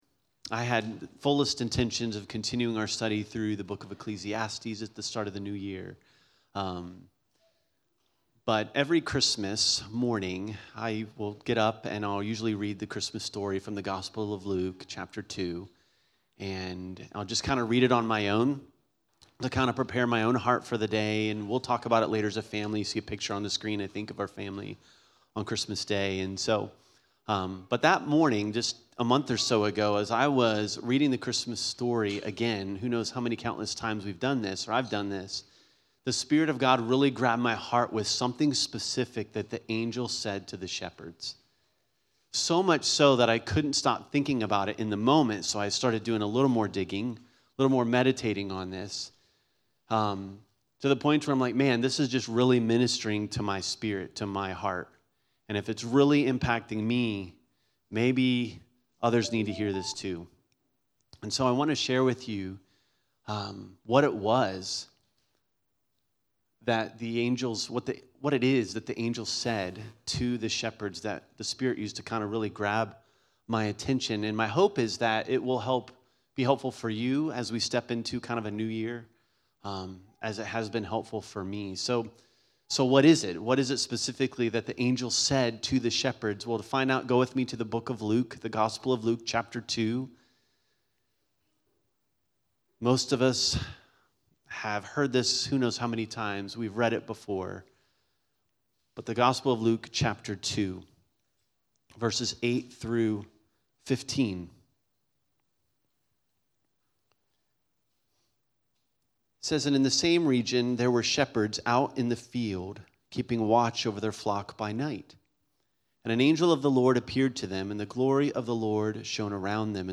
The truth “fear not” is a message throughout the entire Christmas story. Although we've stepped into a new year, today’s teaching takes back into the Christmas story for a much needed truth as we head into this new year.